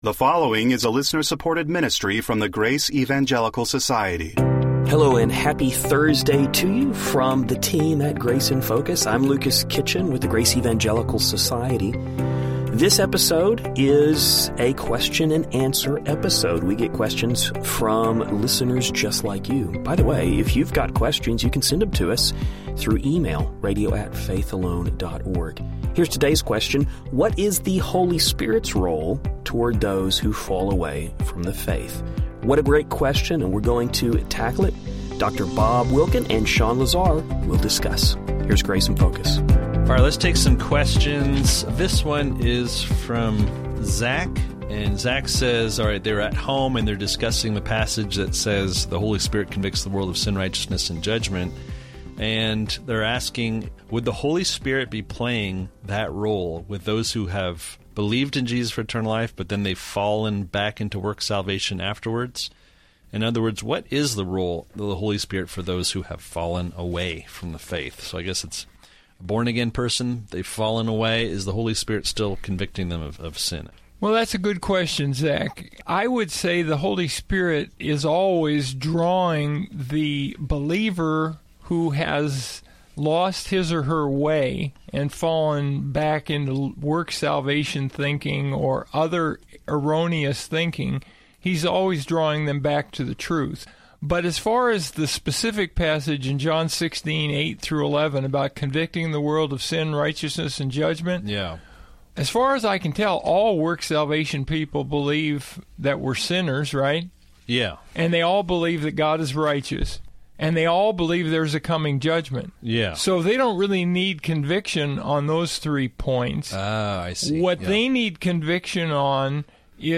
We hope you enjoy the discussion.